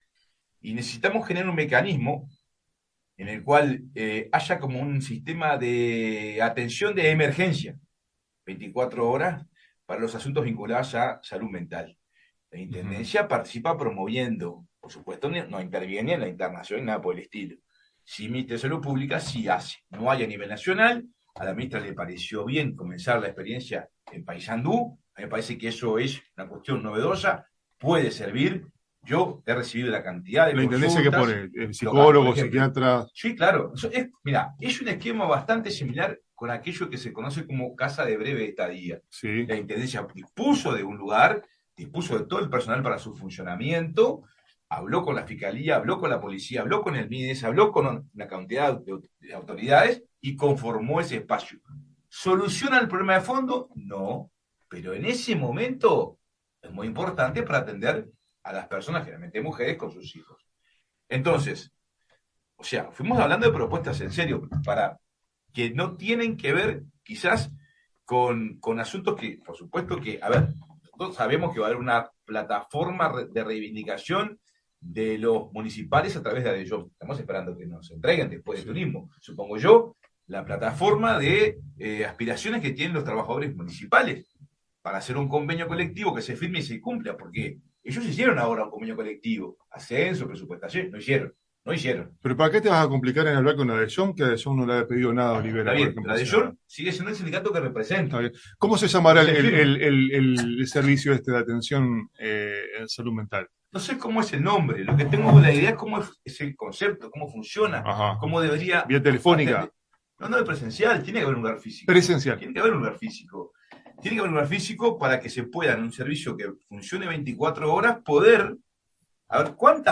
Se trataría de una acción innovadora a nivel nacional en el abordaje de «uno de esos temas en los cuales no podés estar jugando, no podés estar improvisando», dijo Caraballo en La Tribu: